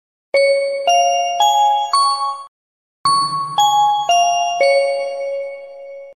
• Качество: 256, Stereo
объявление на посадку
Звук объявления на посадку в Аэропорту